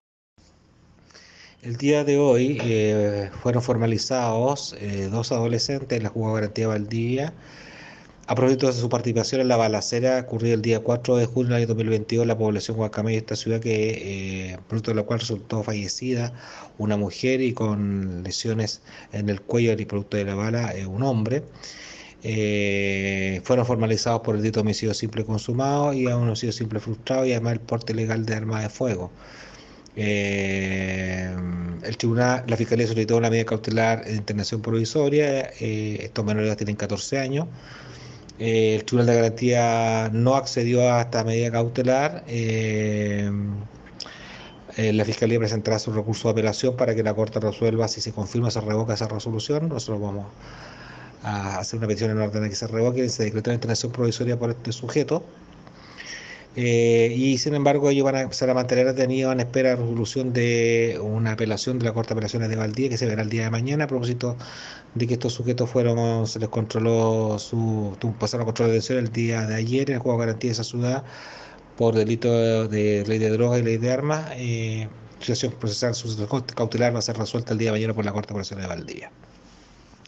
Fiscal José Rivas